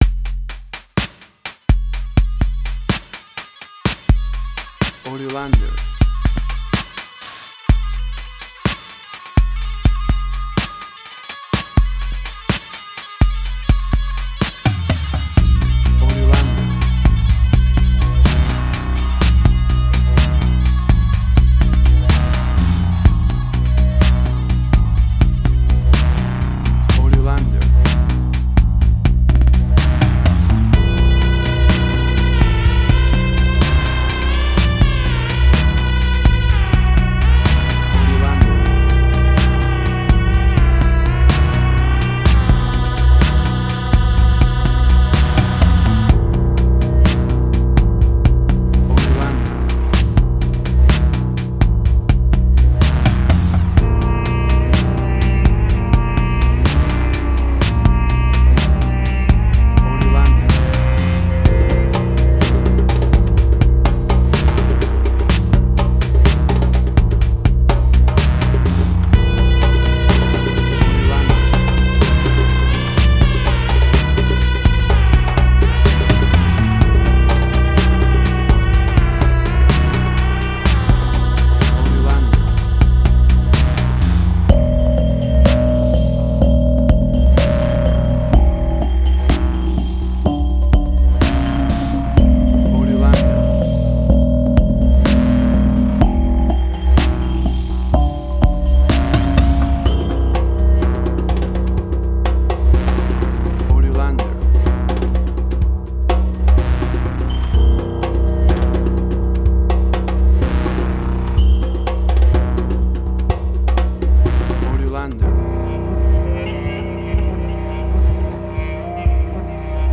Middle Eastern Fusion
Tempo (BPM): 63